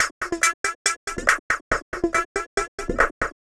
tx_perc_140_flangeecho.wav